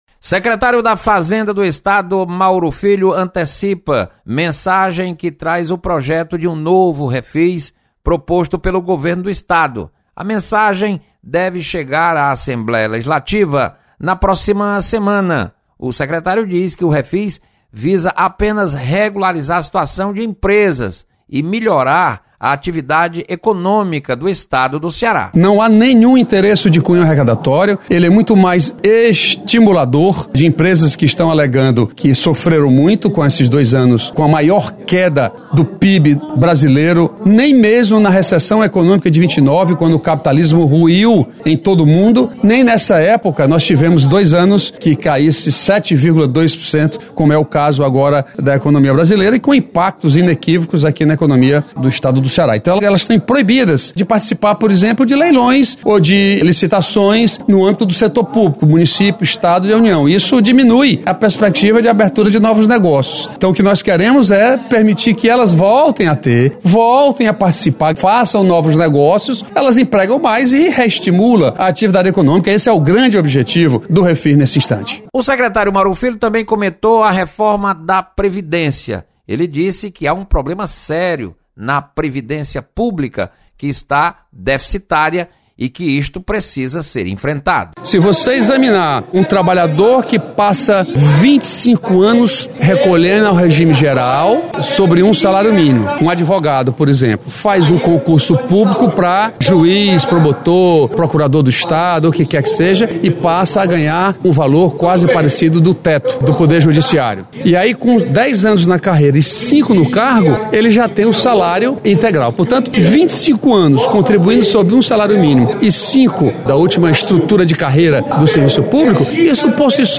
Secretário Mauro Filho defende reforma da previdência.